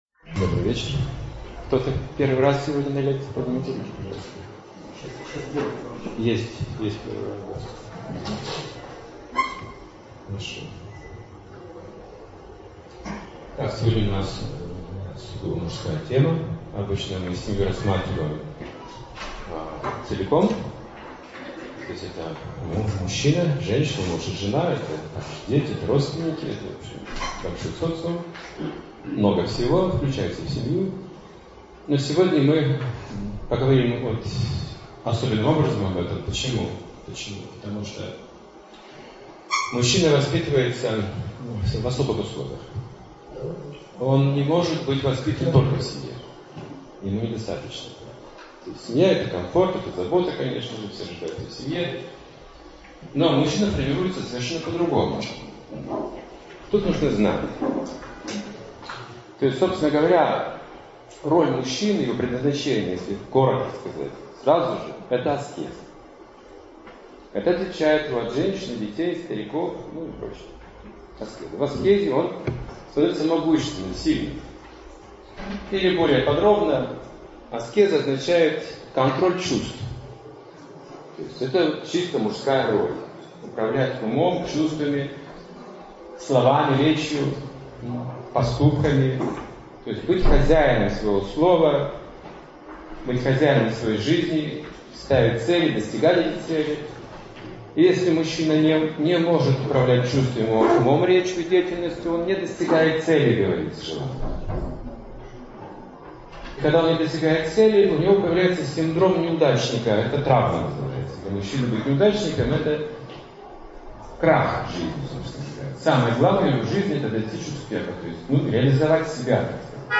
Лекция о знании, доблести, отваге, храбрости, героизме, честности. Как избавиться от страхов и ненужных привязанностей и обрести могущество.